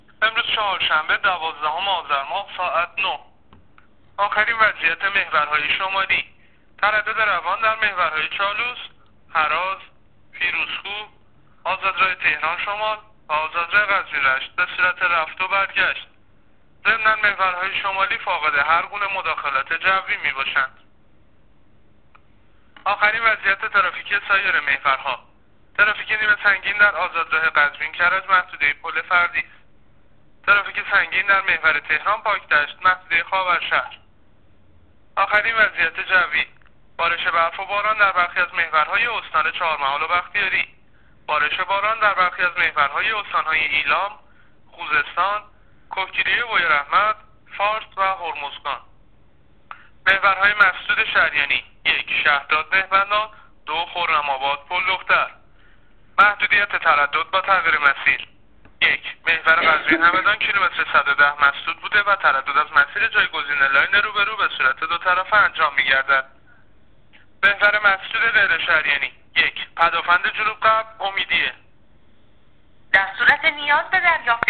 گزارش رادیو اینترنتی از وضعیت جاده‌ها تا ساعت ۹ دوازدهم آذرماه